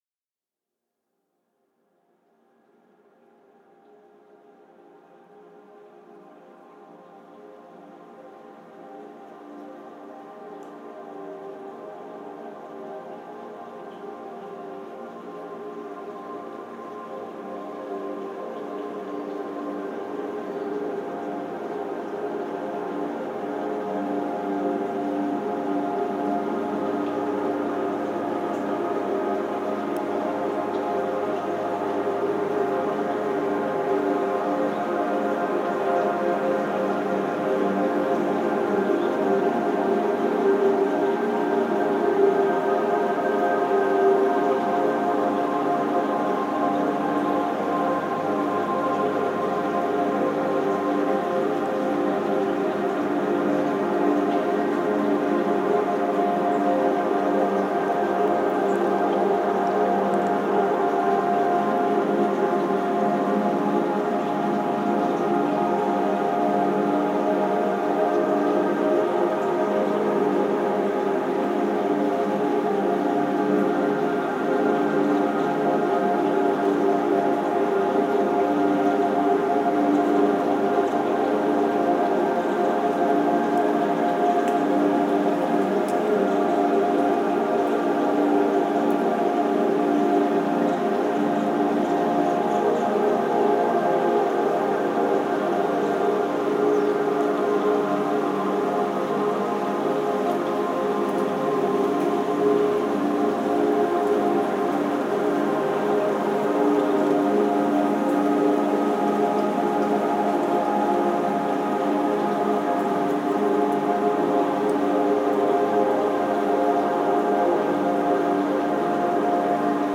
Genre: Ambient/Field Recording.